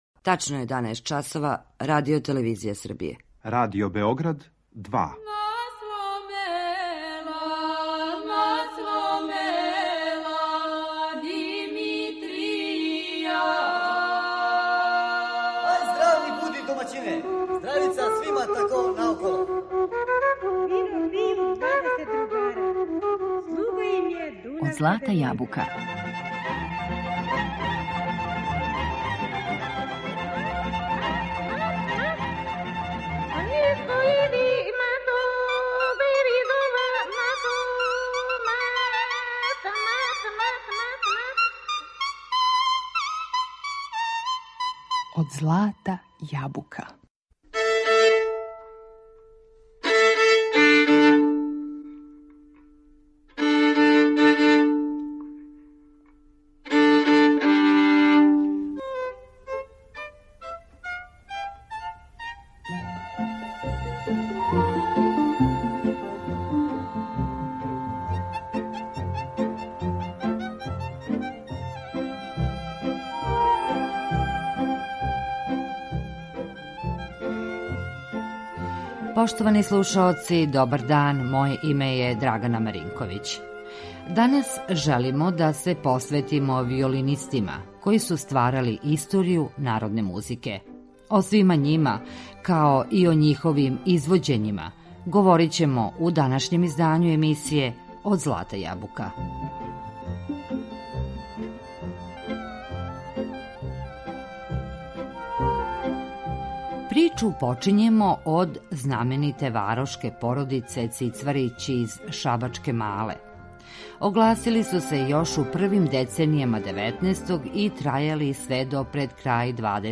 Данашњу емисију Од злата јабука посветили смо знаменитим виолинистима који су стварали историју наше народне музике.